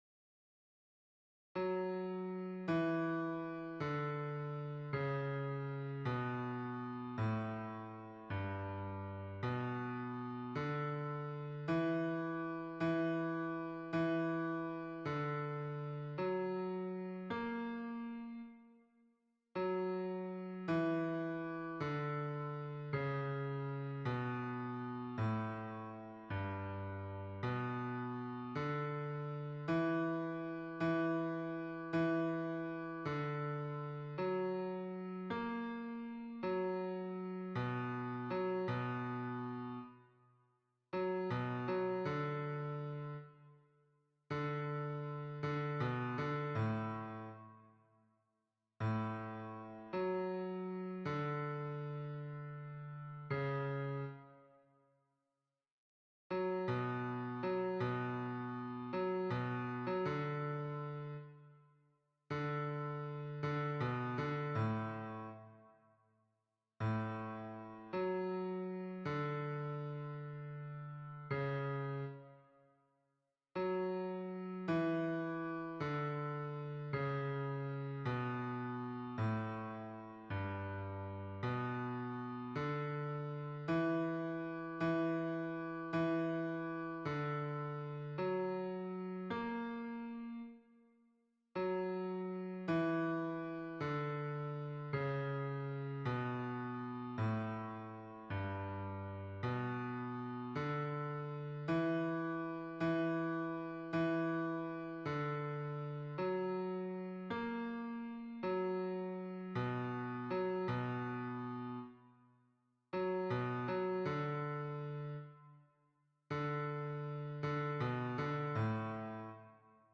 Baryton